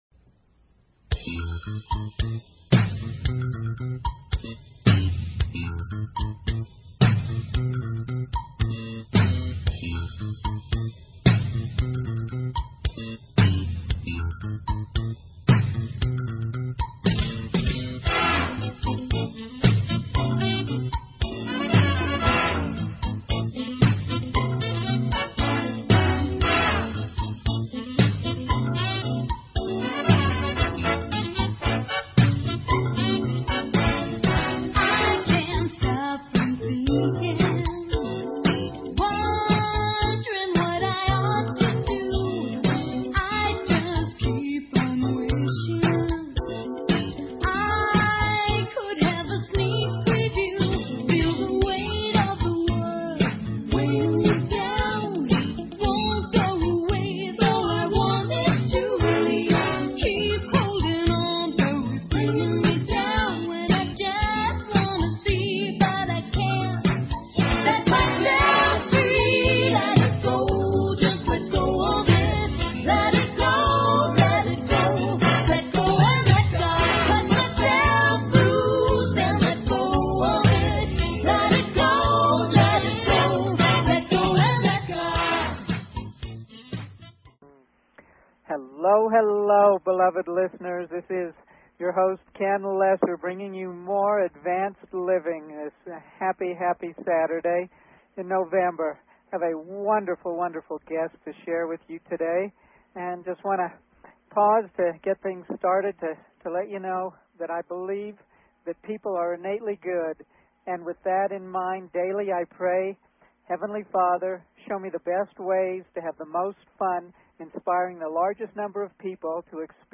Energy Clearing Advanced Living Please consider subscribing to this talk show.